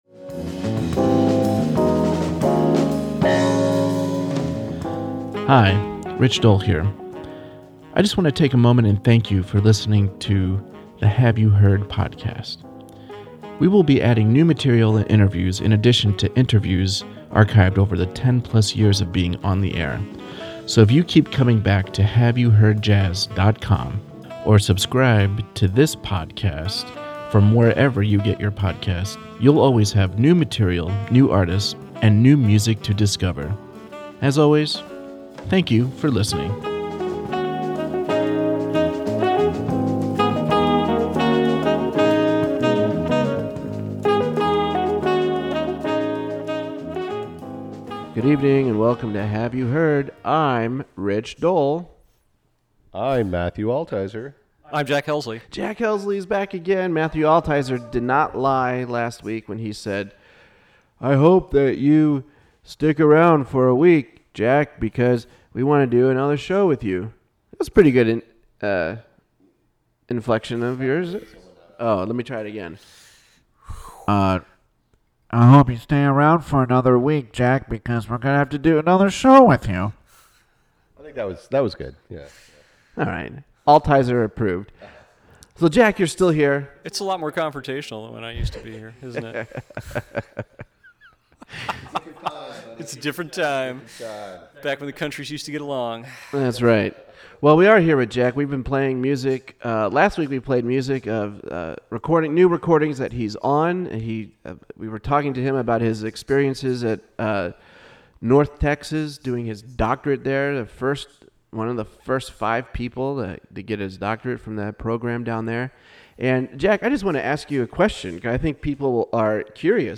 This is a continuation of our conversation
in my living room.